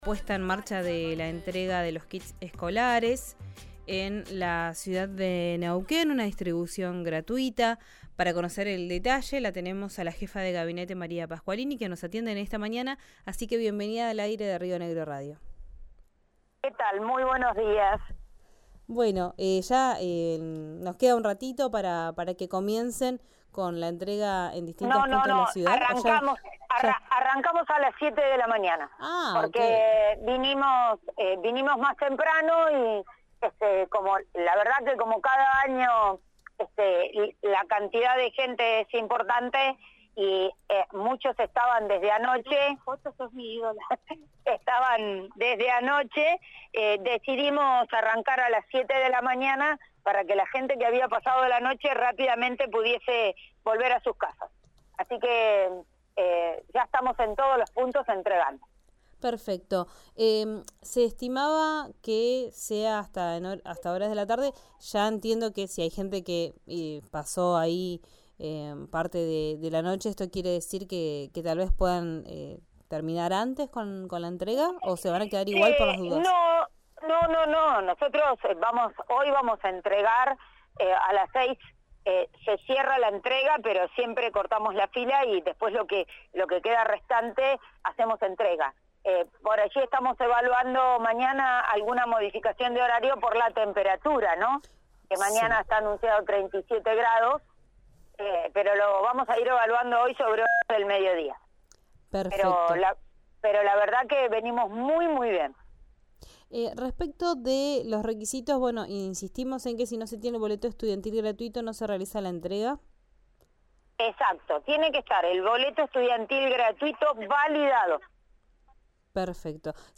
Escuchá a María Pasqualini, jefa de Gabinete de la municipalidad de Neuquén, en RÍO NEGRO RADIO: